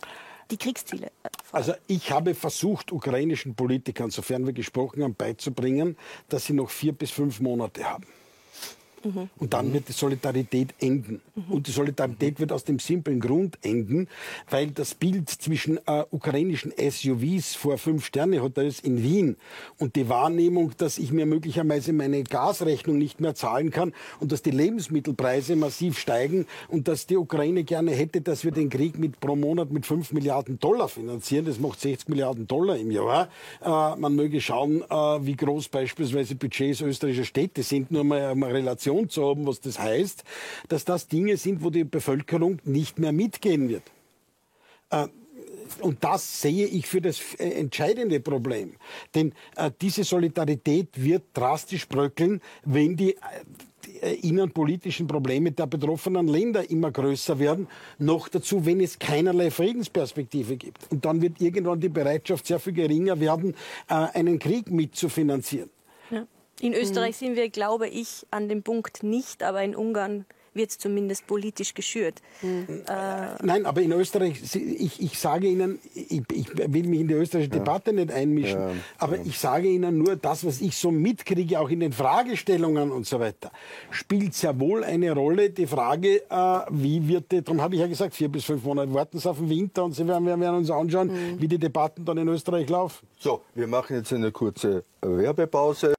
ORF-Kriegsreporter Christian Wehrschütz war zu Besuch in Wien. Im Club 3 erzählt er aus seinem Recherchealltag in der Ukraine und sagt dabei: Die Solidarität mit der Ukraine wird in spätestens 4-5 Monaten enden.